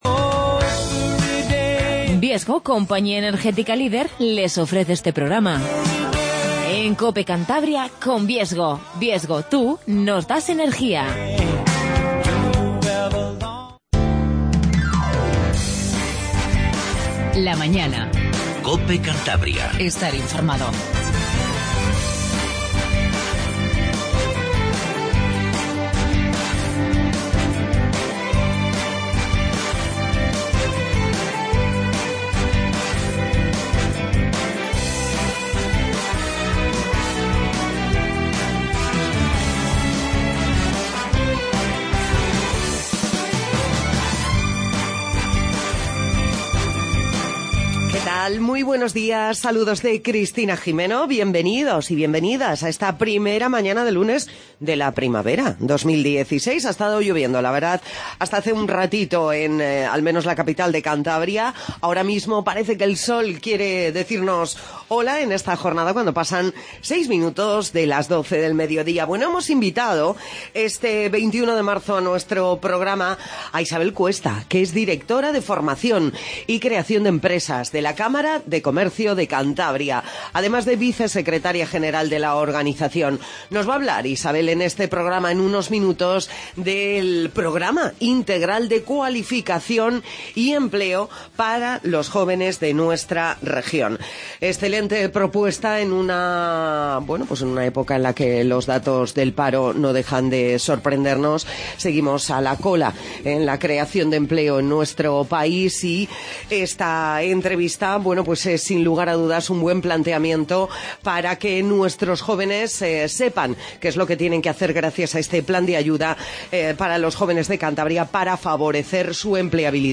Magazine